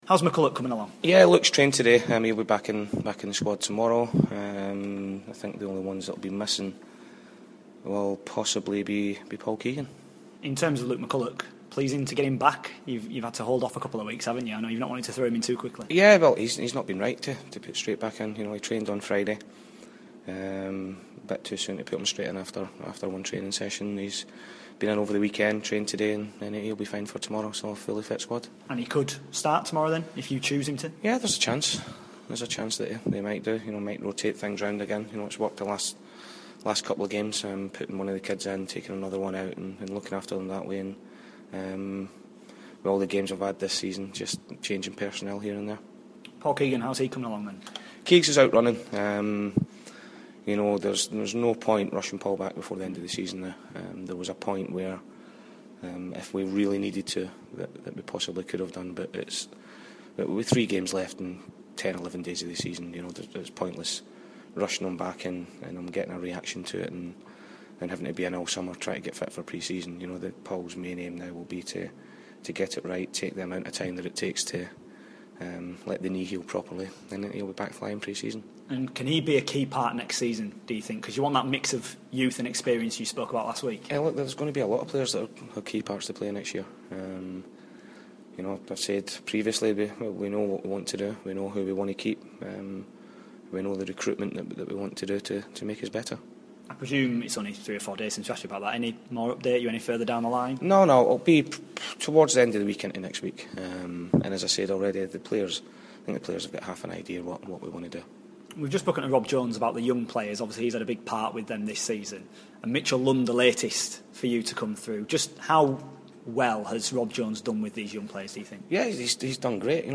INTERVIEW: Doncaster Rovers manager Paul Dickov ahead of their trip to MK Dons.